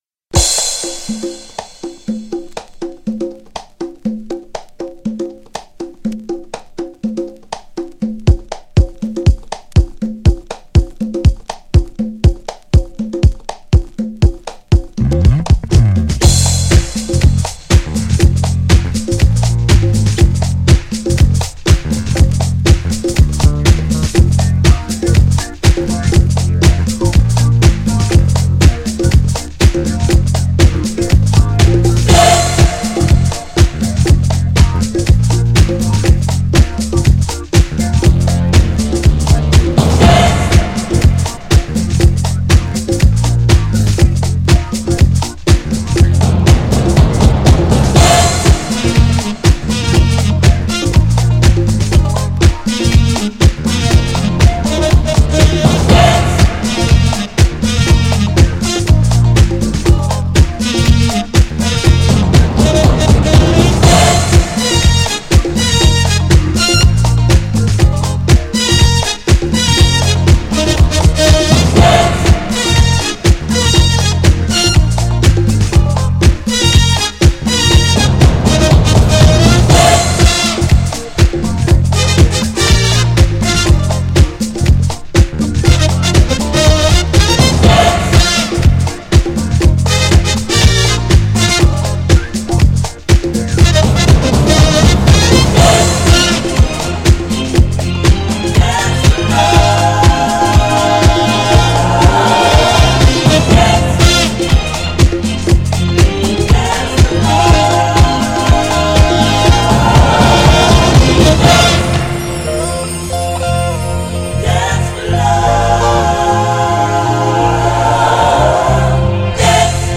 GENRE House
BPM 126〜130BPM